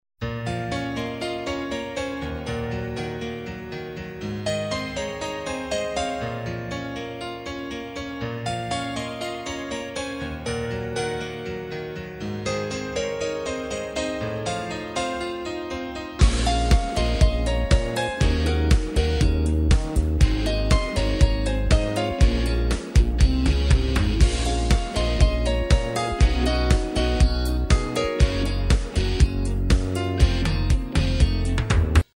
Инструментальная часть